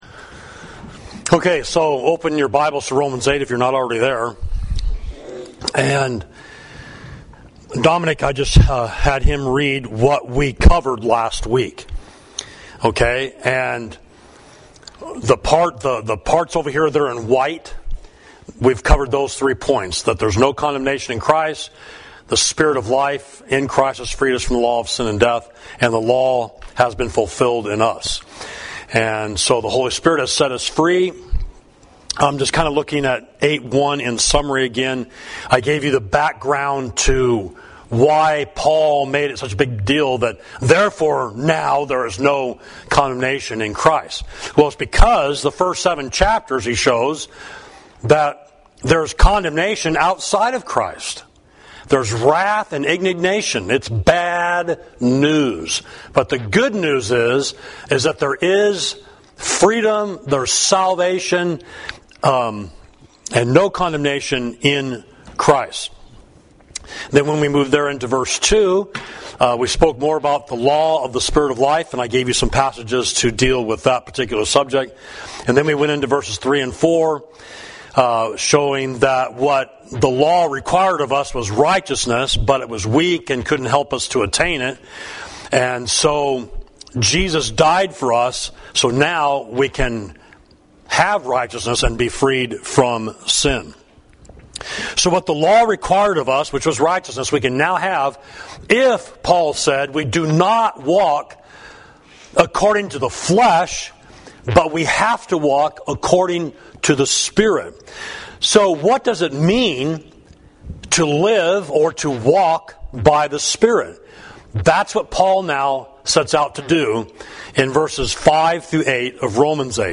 Sermon: The Holy Spirit in Romans 8, Part 2 – Savage Street Church of Christ